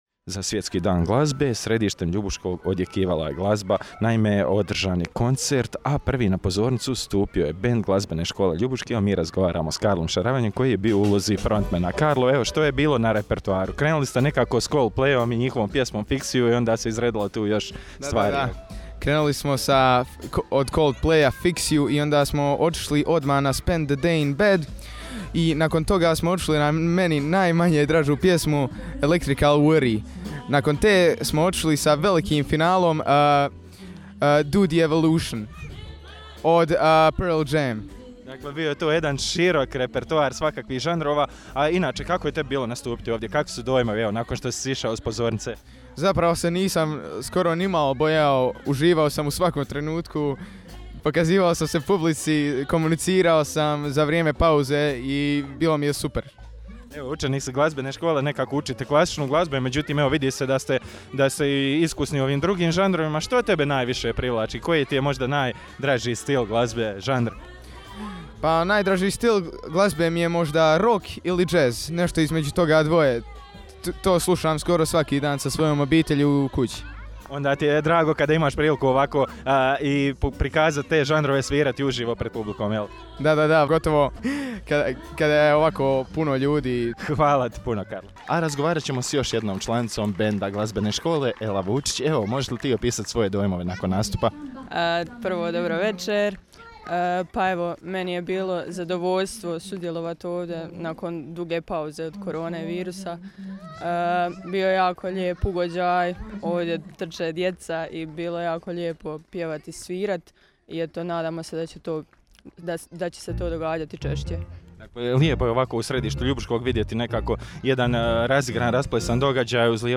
Na glavnom gradskom trgu je odjekivala glazba i pjesma, a za kvalitetnu dvosatnu zabavu pobrinuli su se band Osnovne glazbene škole Ljubuški “Rockplay, band Srednje glazbene škole Ljubuški “Little Bambie Groove” i jazz kvartet Napretkove podružnice Ljubuški “Birdland”.
Oni su izveli niz jazz i blues skladbi, a koncert su zatvorili izvedbom instrumentalne jazz-fusion skladbe “Spain” u čast legendarnog pijanista i kompozitora Chick Coree koji je preminuo početkom ove godine.
koncert-na-trgu.mp3